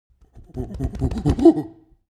Excited Monkey Thumping Chest Call Bouton sonore